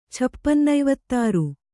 ♪ chapannaivattāru